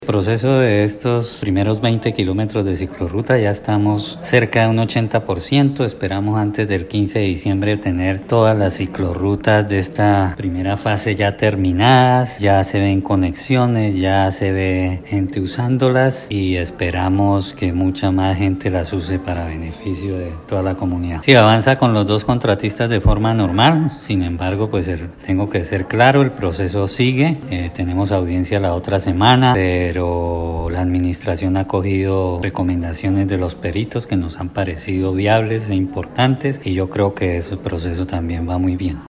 Descargue audio: Iván Vargas, secretario de Infraestructura